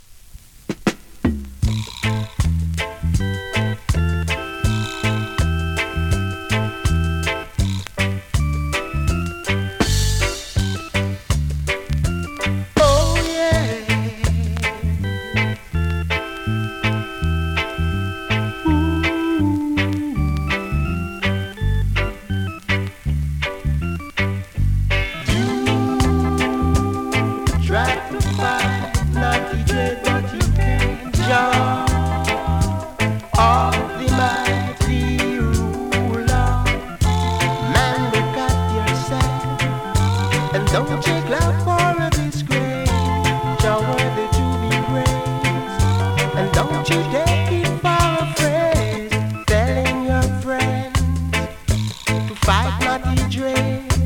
2025!! NEW IN!SKA〜REGGAE
スリキズ、ノイズかなり少なめの